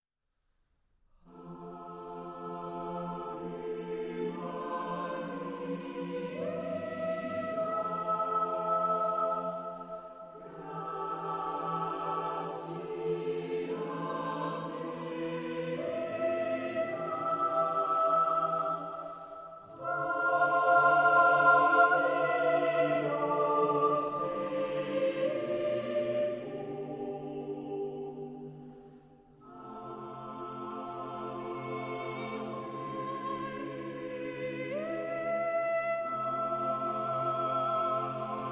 SSAATTBB (8 voix mixtes) ; Partition complète.
Hymne (sacré). Motet.
Consultable sous : 20ème Sacré Acappella